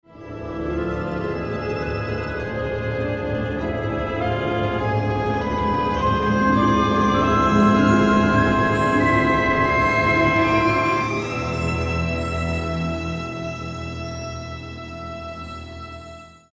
Мелодия появления джина из волшебной лампы